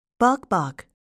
듣기반복듣기 미국 [bάkbk]